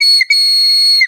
ihob/Assets/Extensions/CartoonGamesSoundEffects/Train_v1/Train_v2_wav.wav at master
Train_v2_wav.wav